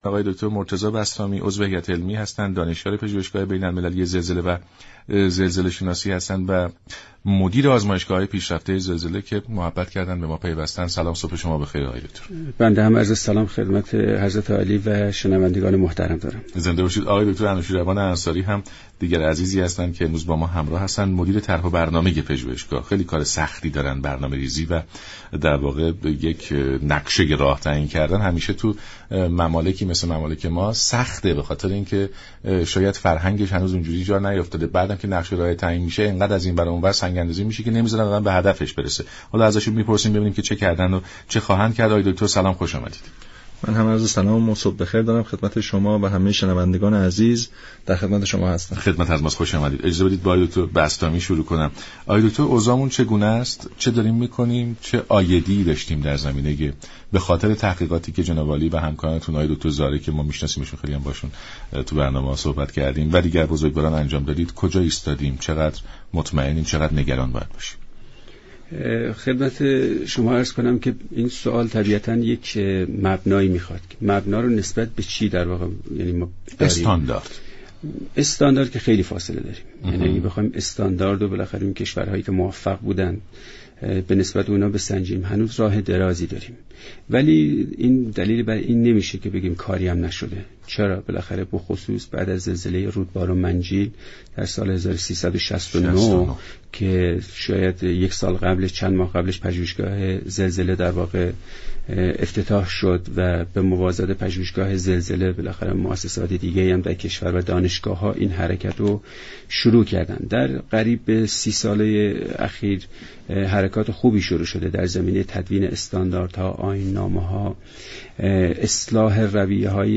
در بخش دیگر این گفت و گوی رادیویی